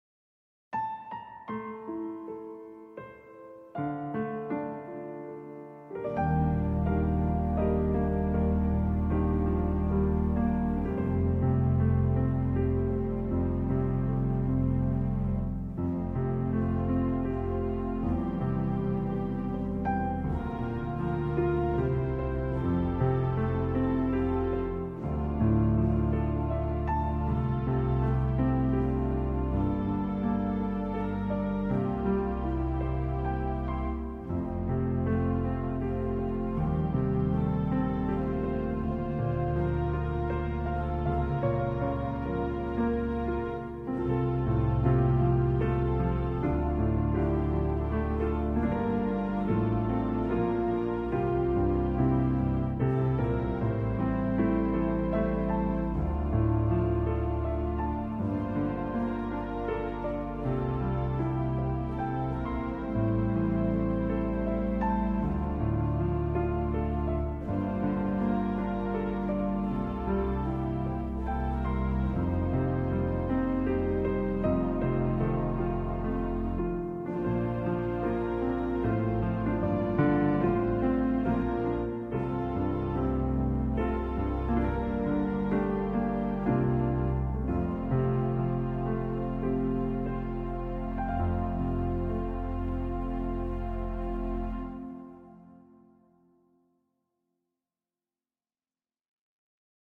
Piano & Organ